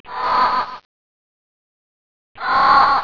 Crow Screaming Sound Effect Free Download
Crow Screaming